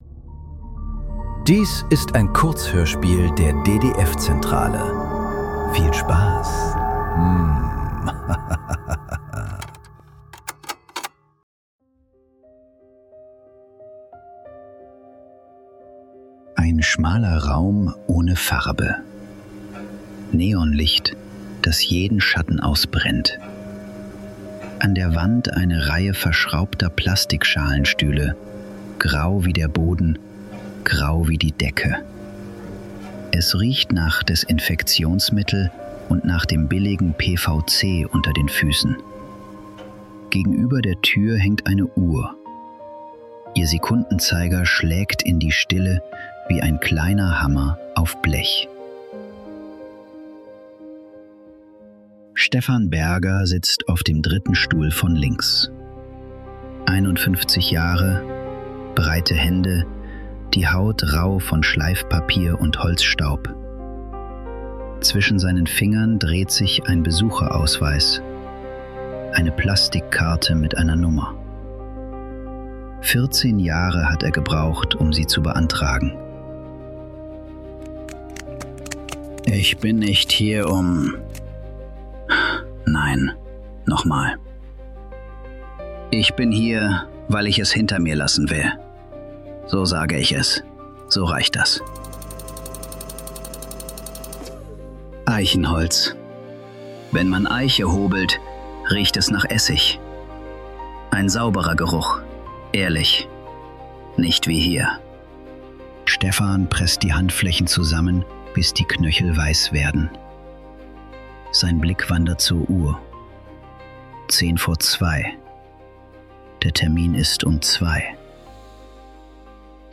Besuchszeit ~ Nachklang. Kurzhörspiele. Leise. Unausweichlich. Podcast